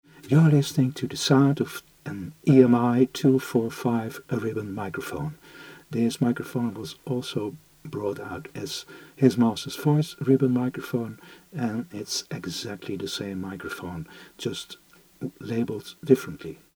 Like most ribbon microphones, it has a bidirectional pickup pattern, its output is rather low, because of the 20 Ohm transformer, which was perfect for older equipment.
These microphones have found their way to recording studios and although their sound is often pretty dark, even for a ribbon microphone, whith a fresh ribbon, and modern transformer, they deliver good audio.
EMI PM 245 sound UK.mp3